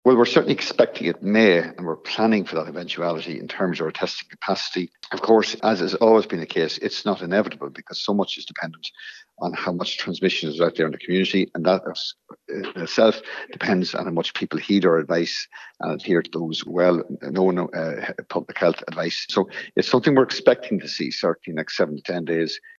The HSE’s Chief Clinical Officer, Colm Henry, says he’s preparing for 2,000 cases a day by the end of this month: